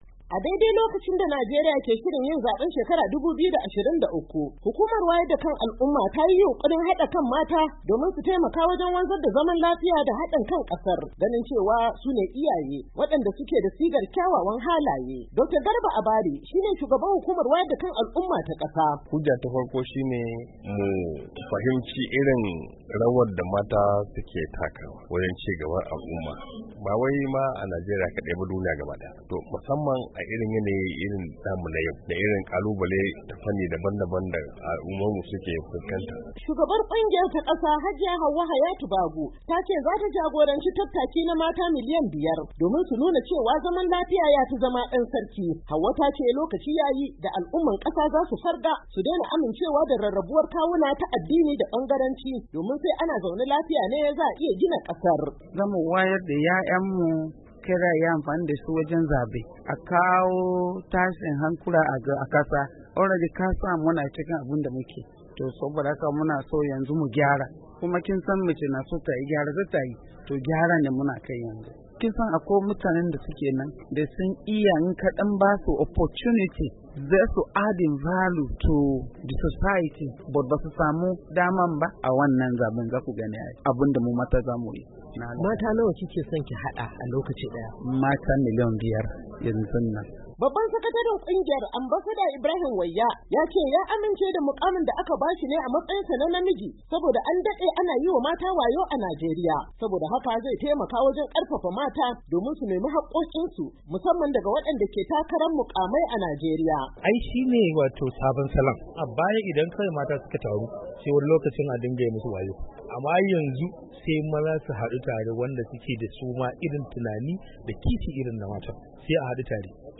Ga rahoton cikin sauti: